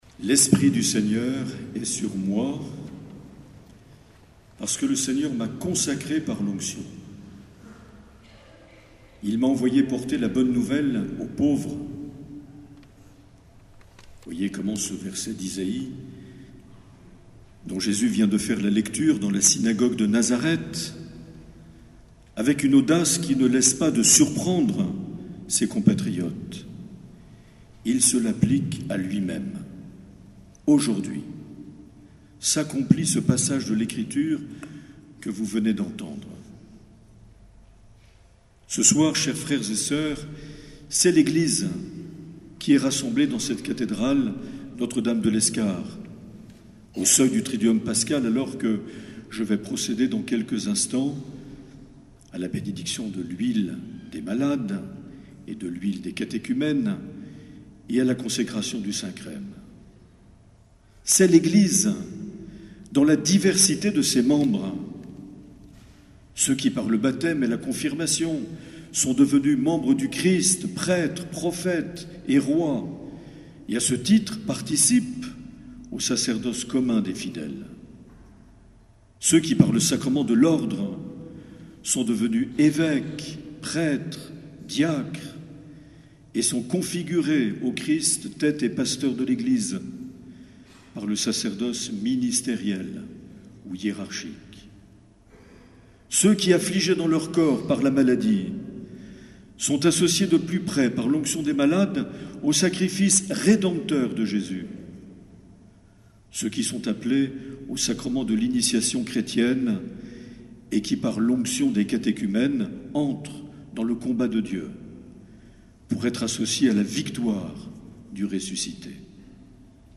16 avril 2019 - Cathédrale de Lescar - Messe Chrismale